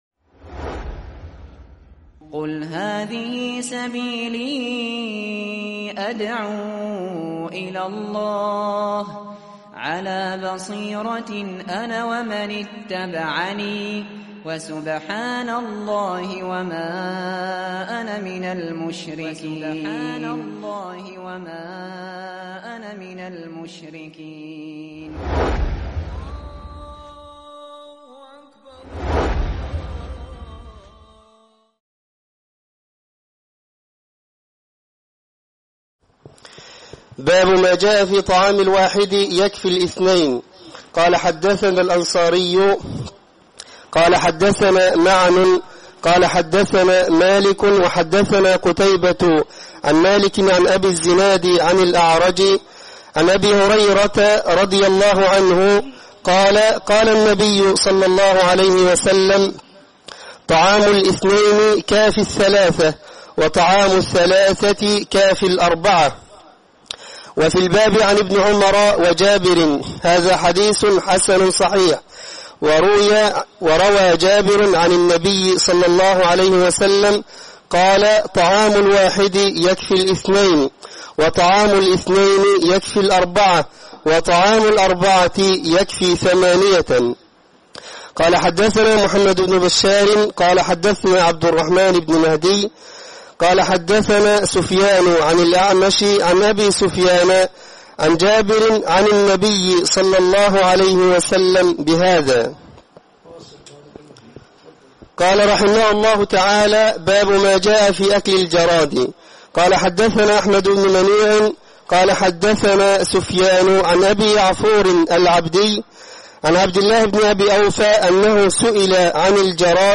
شرح سنن الترمذي